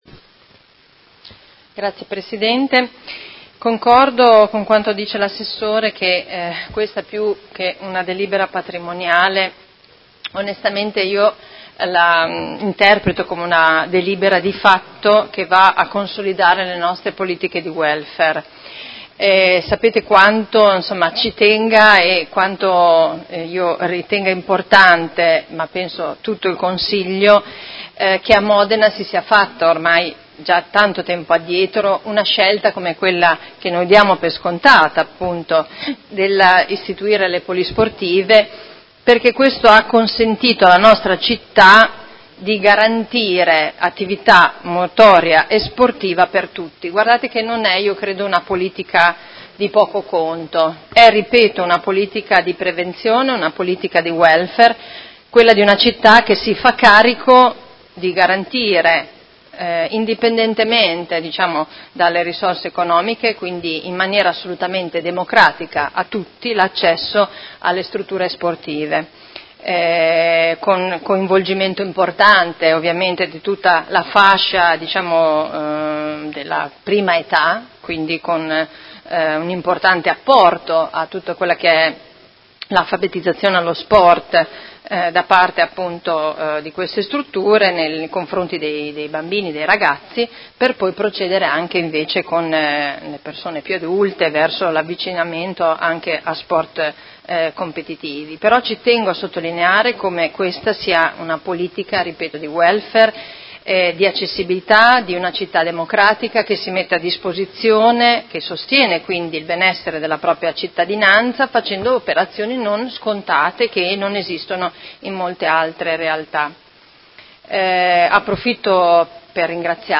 Seduta del 7/03/2019 Dibattito.